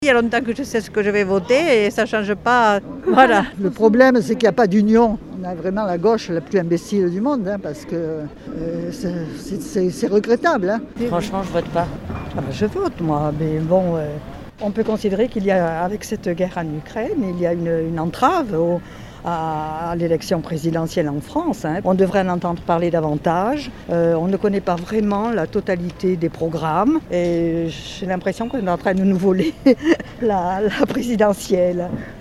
La guerre en Ukraine va-t-elle peser sur votre décision ? On est allé vous poser la question dans les rues de Nice.